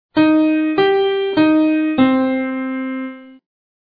Minor third followed by major third